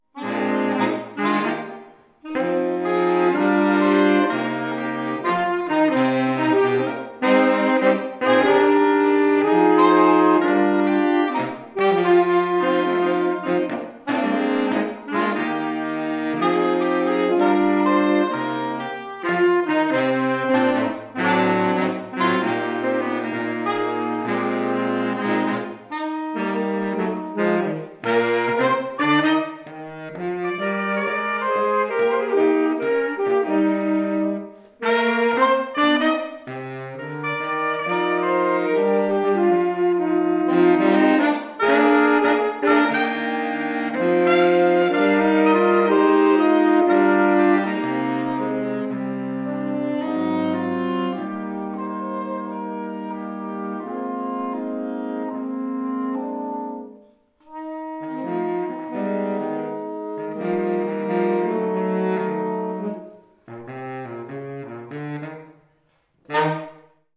Quite a different sound from a ceilidh band, but saxes can play folk tunes with the best of them – whether that’s a soulful ballad or a lively jig to set your toe tapping!